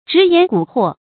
直言賈禍 注音： ㄓㄧˊ ㄧㄢˊ ㄍㄨˇ ㄏㄨㄛˋ 讀音讀法： 意思解釋： 直：坦率、直爽；賈：買，引伸為招致。